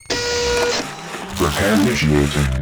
beginrepair.wav